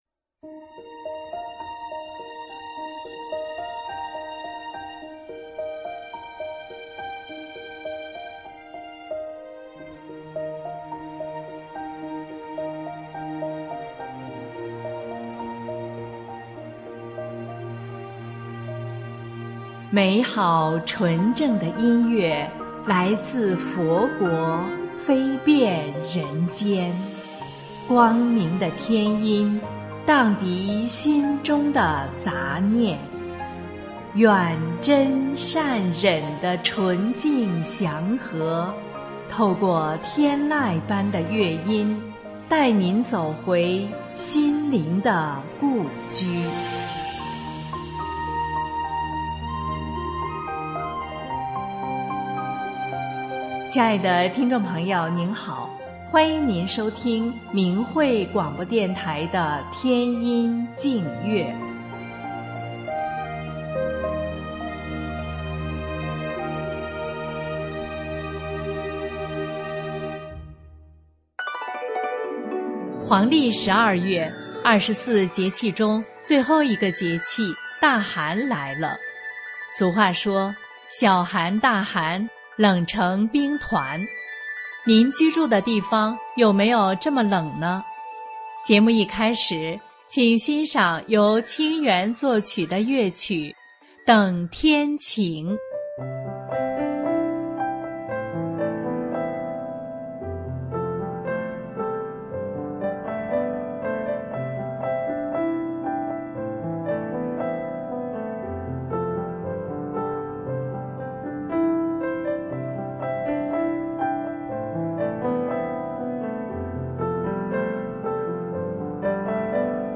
乐曲
男声独唱
合唱